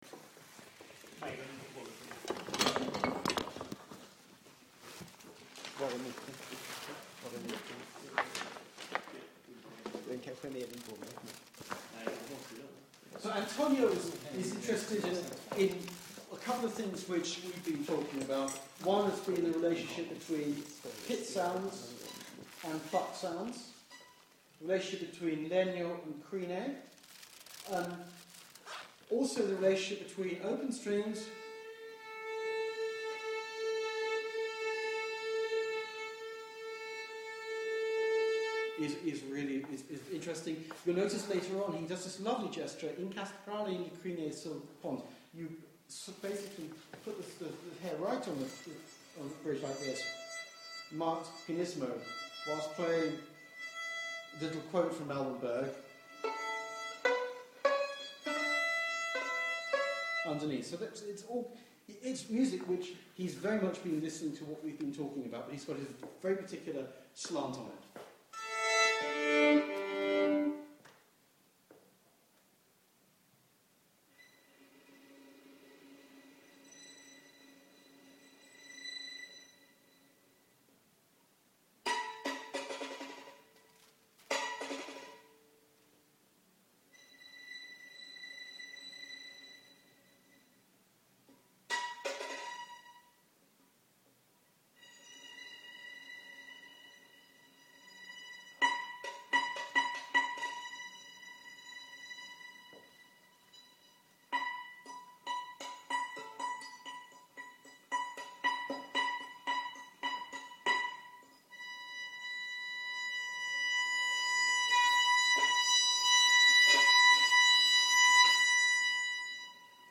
Workshop performance-Malmo May 2011